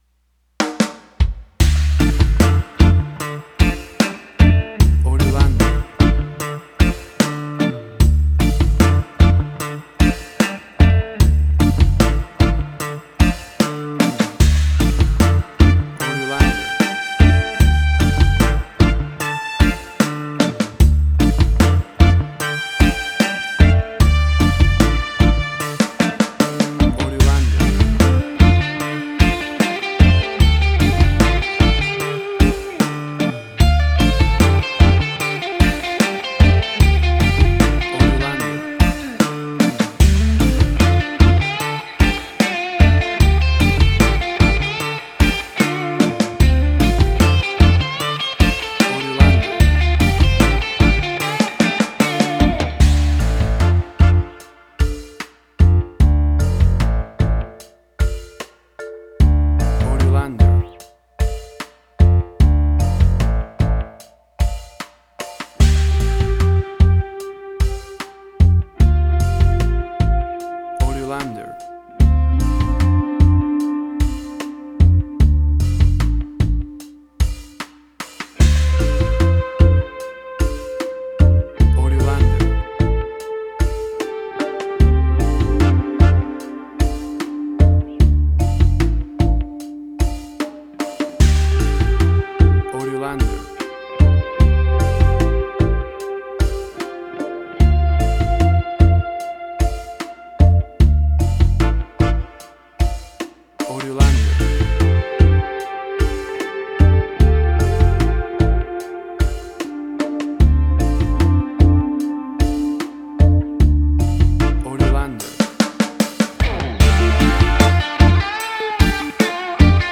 Reggae caribbean Dub Roots
Tempo (BPM): 75